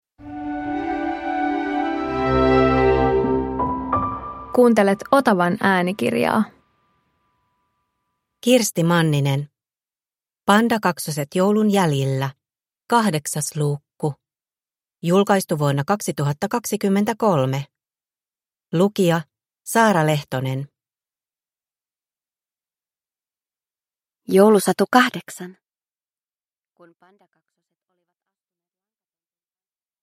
Pandakaksoset joulun jäljillä 8 – Ljudbok